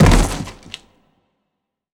anonTheaterFall.wav